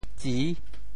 潮州府城POJ tsĭ 国际音标 [tsi]